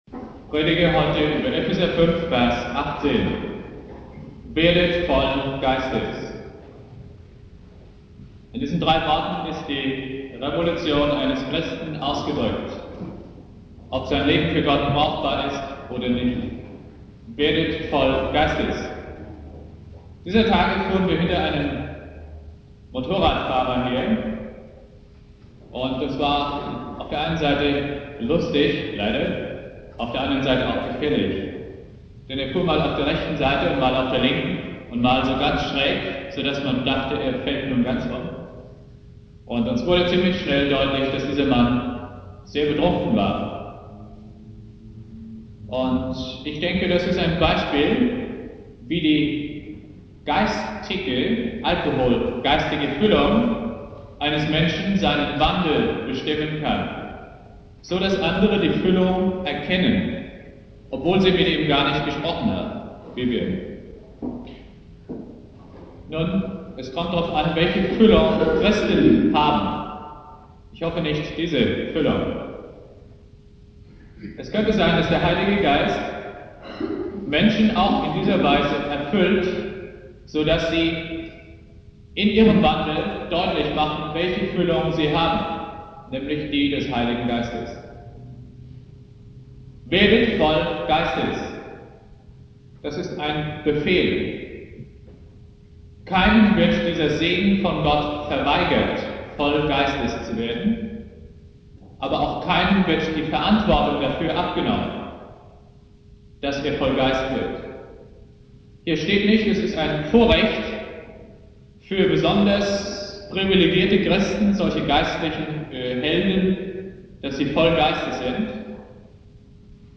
Pfingstmontag Prediger